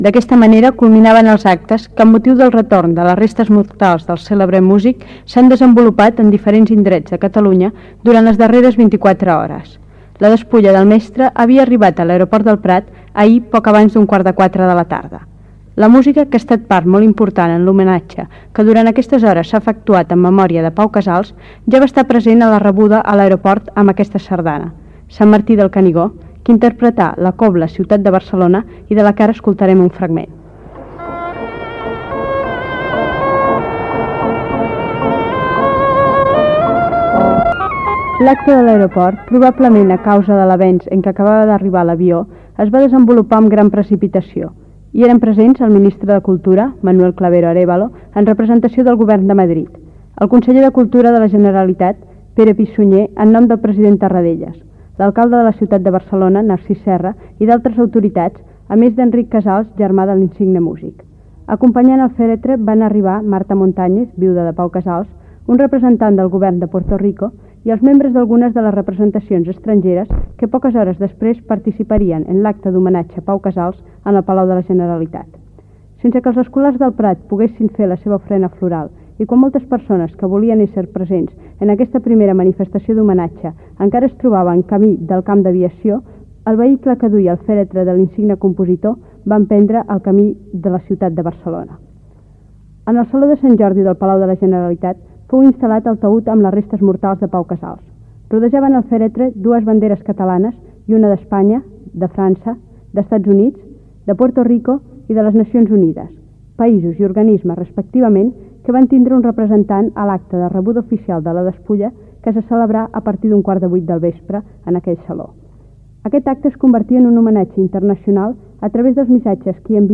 Retorn a Catalunya de les despulles del músic Pau Casals el dia 10 de novembre de 1979. El ministre de cultura Manuel Clavero Arévalo llegeix un missatge de la casa reial, lectura d'un missatge del president del Govern espanyol Adolfo Suárez, lectura d'un missatge del president francès Giscard d'Estaing, lectura d'un missatge del president nord-americà Jimmy Carter.
Informatiu